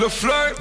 TS - CHANT (15).wav